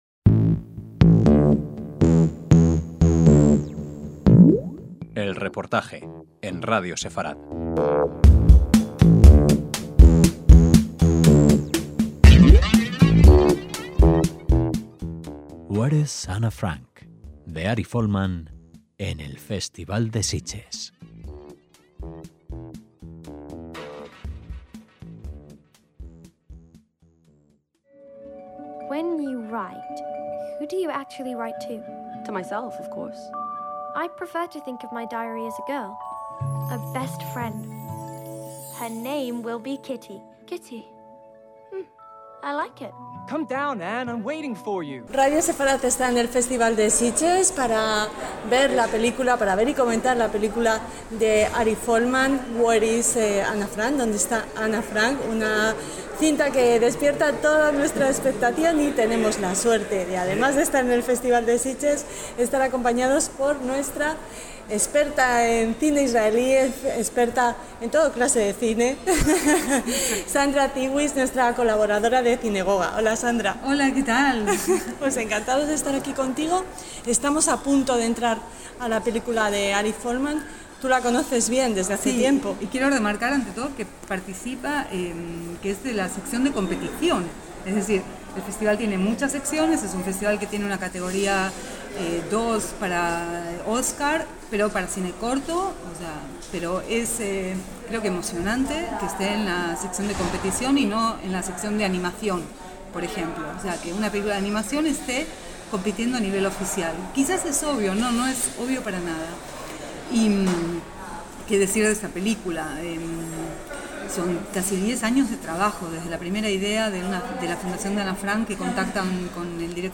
EL REPORTAJE - Se proyectaba Where is Anne Frank la última película de Ari Folman en el Festival de Sitges y Radio Sefarad estuvo allí.